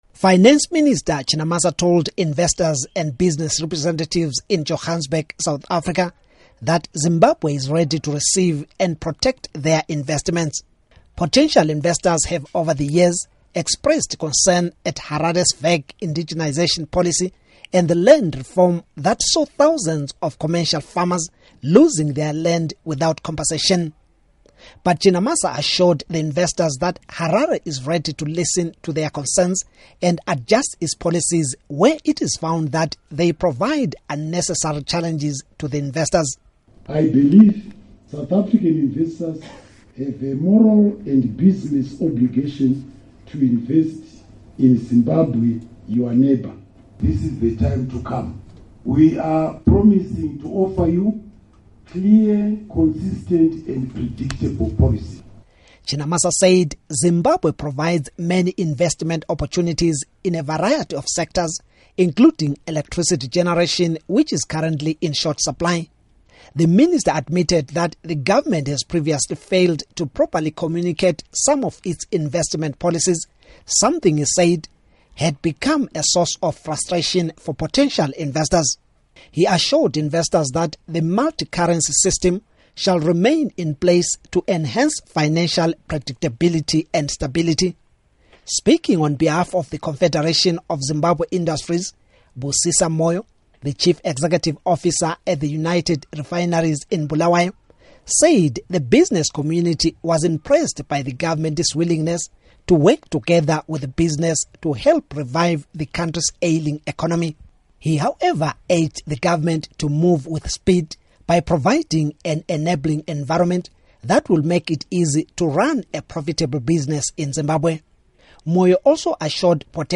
Report on South African Investors